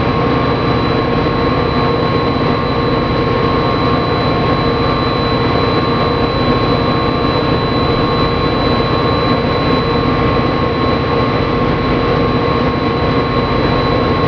Here's one with a little more bass and is longer.
F40 OUT of cab LONG/BASS
F40editmonoLONGER2.wav